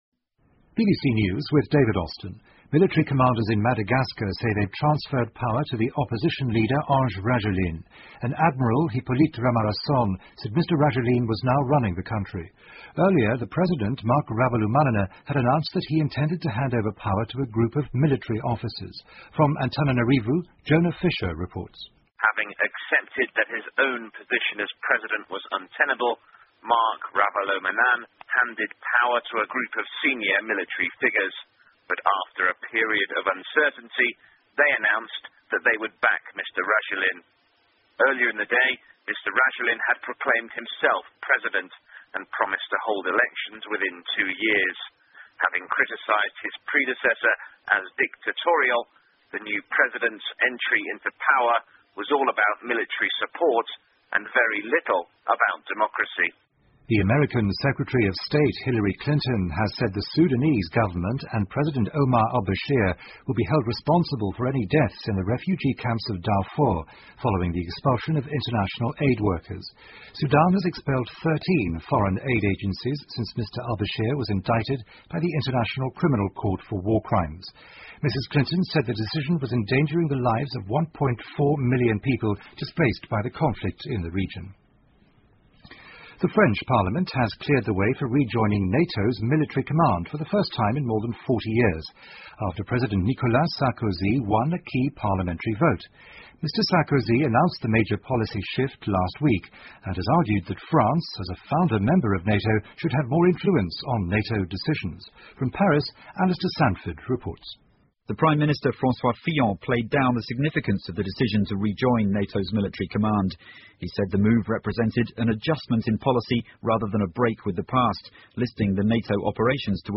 英国新闻听力 法国重入北约指挥系统 听力文件下载—在线英语听力室